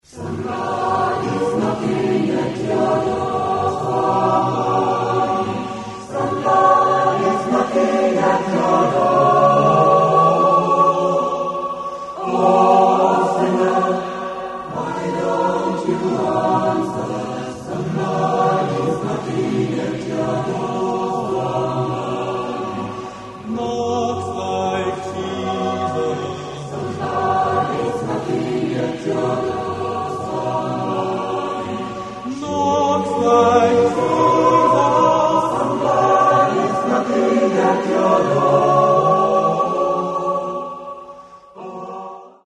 Demo-Aufnahmen - größtenteils Live-Mitschnitte.